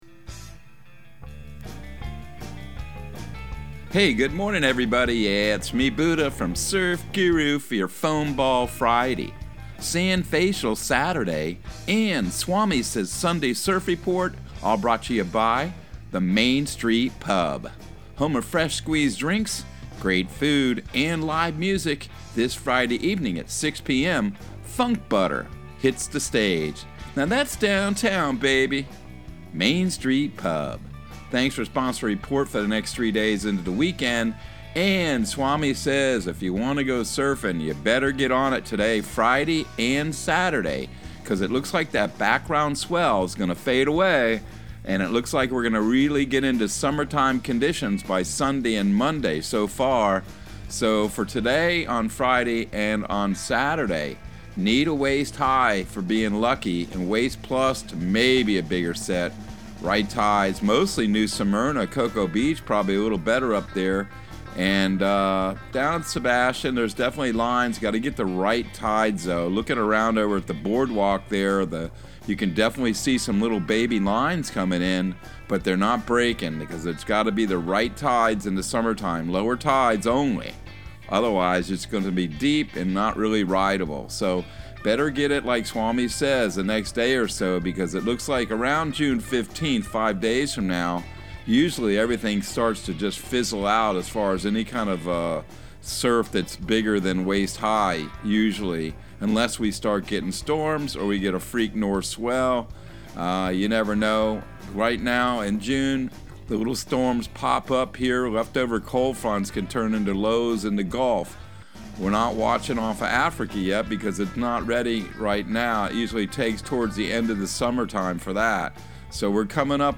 Surf Guru Surf Report and Forecast 06/10/2022 Audio surf report and surf forecast on June 10 for Central Florida and the Southeast.